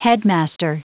Prononciation du mot headmaster en anglais (fichier audio)
headmaster.wav